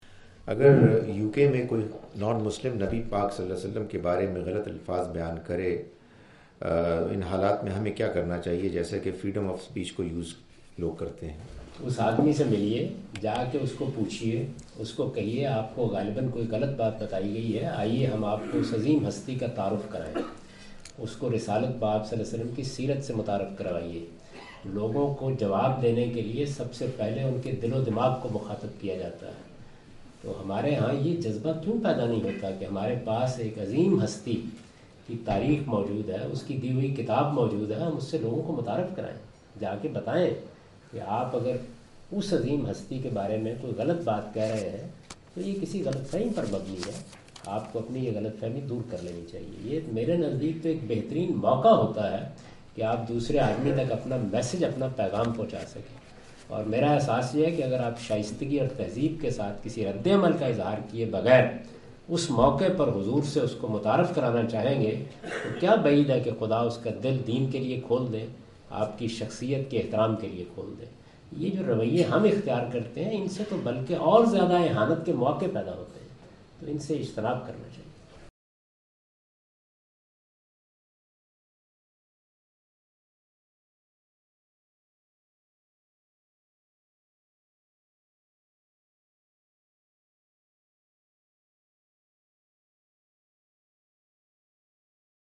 Javed Ahmad Ghamidi answer the question about "blasphemy and freedom of speech" during his visit to Manchester UK in March 06, 2016.
جاوید احمد صاحب غامدی اپنے دورہ برطانیہ 2016 کے دوران مانچسٹر میں "توہینِ مذہب اور آذادی اظہار" سے متعلق ایک سوال کا جواب دے رہے ہیں۔